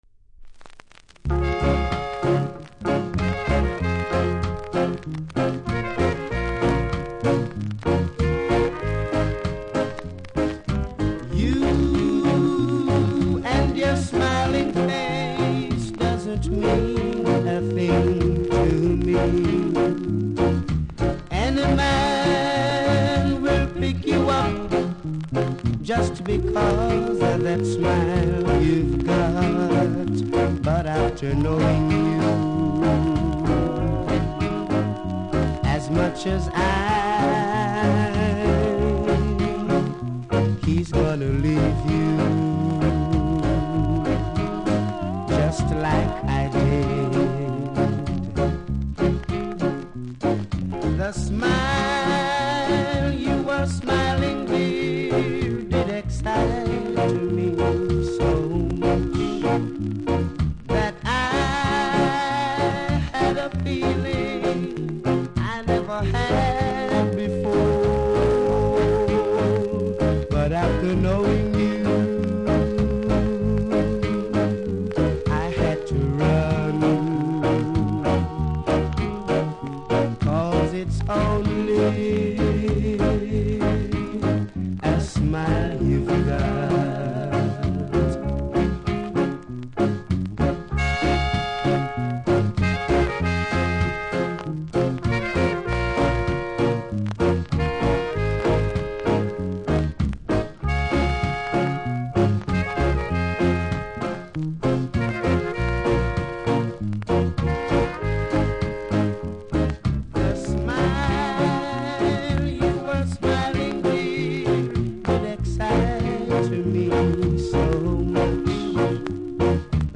所々強めのノイズ拾います。
両面フルで録音しましたので試聴で確認下さい。